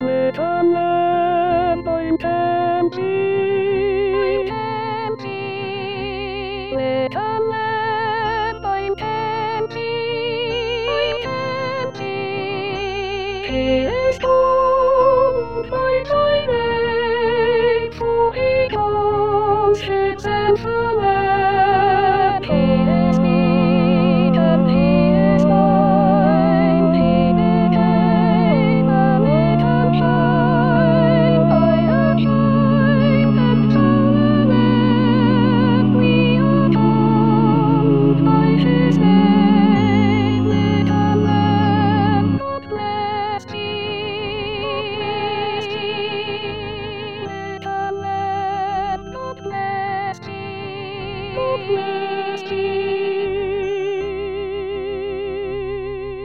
Chanté: